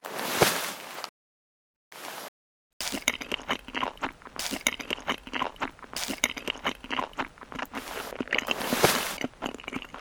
eat_rat.ogg